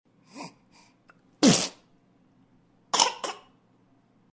Звуки кашля ребенка
Звук новорожденного чиха и кашля